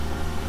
engine1.wav